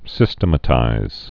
(sĭstə-mə-tīz)